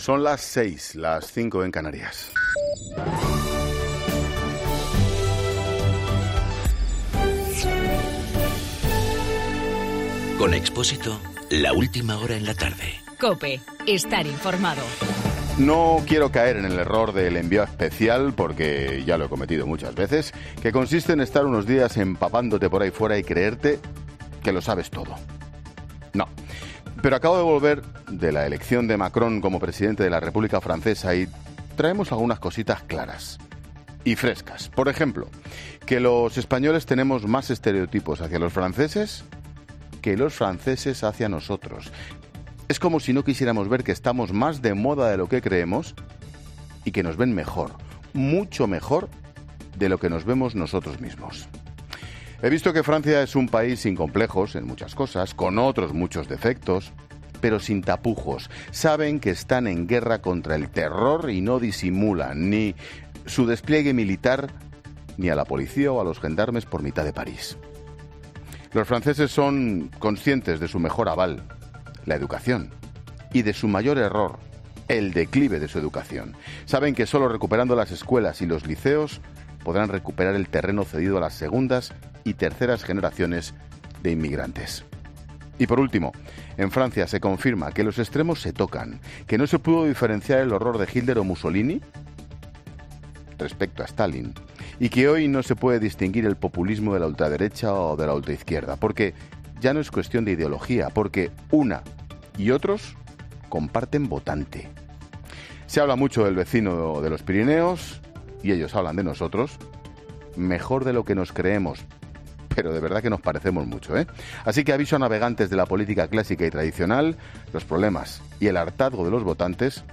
AUDIO: Monólogo 18h.